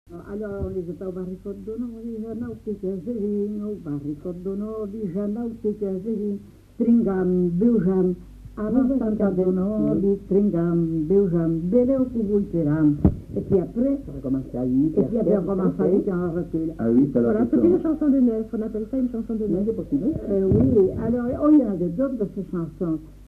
Aire culturelle : Marsan
Genre : chant
Effectif : 1
Type de voix : voix de femme
Production du son : chanté
Classification : chansons de neuf